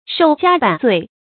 ㄕㄡˋ ㄐㄧㄚ ㄅㄢˇ ㄗㄨㄟˋ